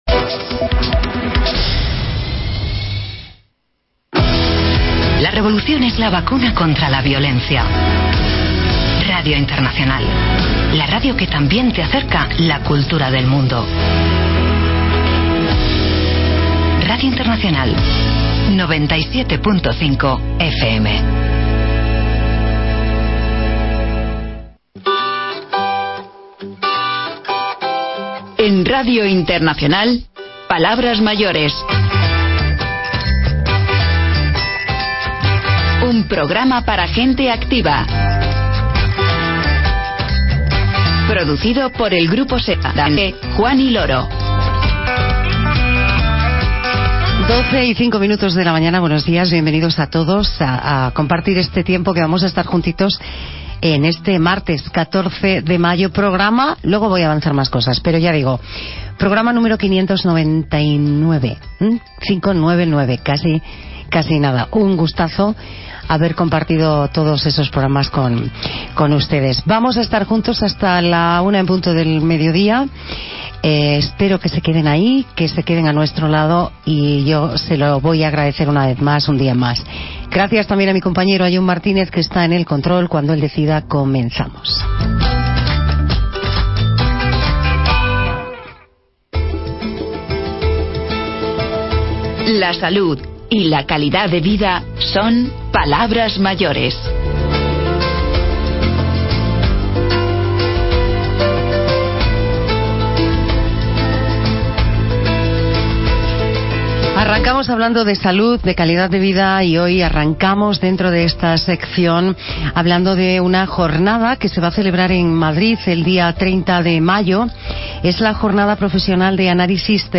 Y cerramos el programa a ritmo de fado, conversando con António Zambuj o,, uno de los protagonistas del IX Festival Internacional de Fado de Madrid , que se celebrará del 21 al 23 de junio en el Teatro Nuevo Apolo de Madrid .